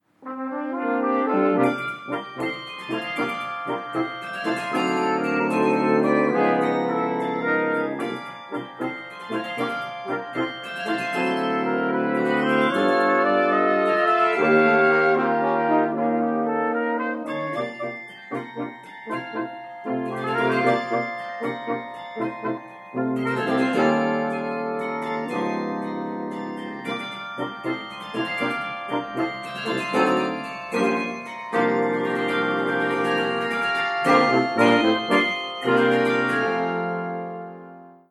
The instrument consists of 48 bells in four octaves and is considered a medium-size carillon.
CARILLON_MOBILNY_GDANSK.mp3